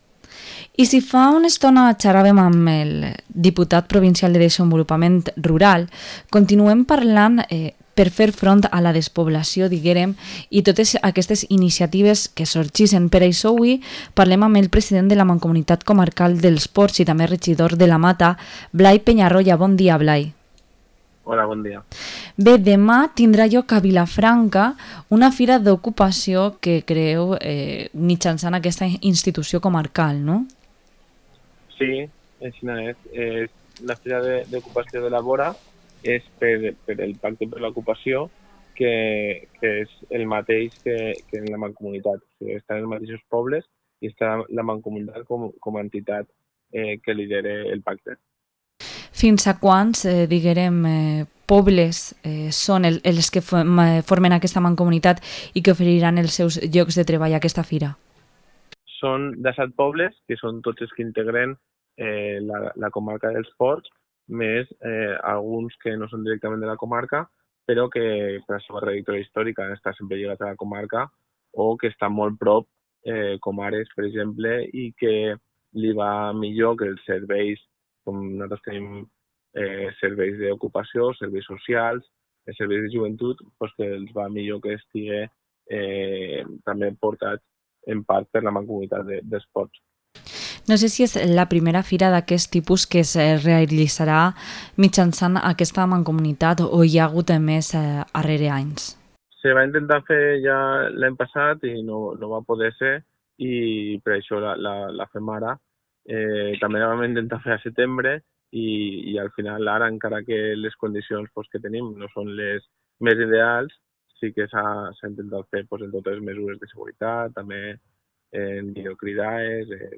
Entrevista al presidente de la Mancomunidad Comarcal dels Ports, Blai Peñarroya